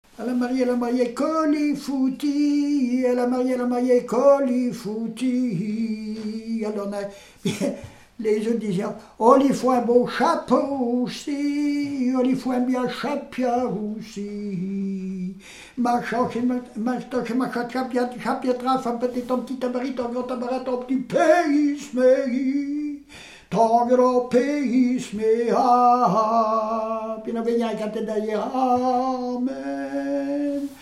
Mémoires et Patrimoines vivants - RaddO est une base de données d'archives iconographiques et sonores.
Genre énumérative
Catégorie Pièce musicale inédite